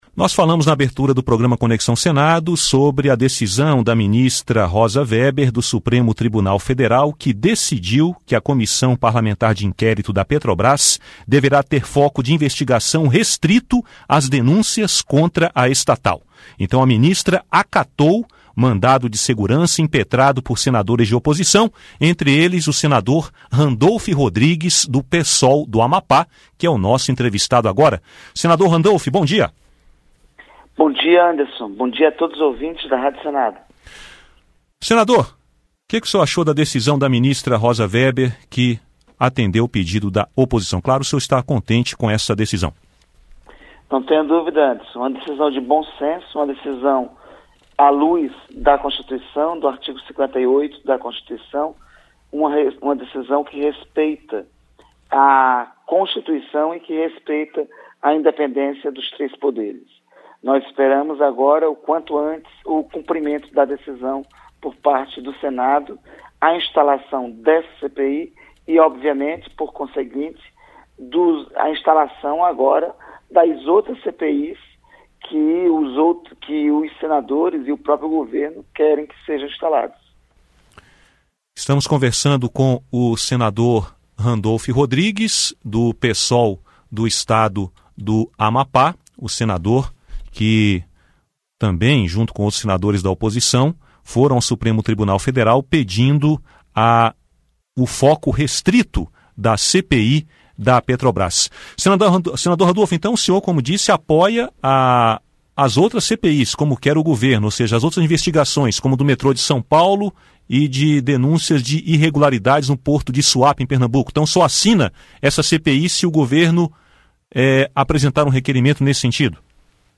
Entrevista com o senador Randolfe Rodrigues (PSOL-AP).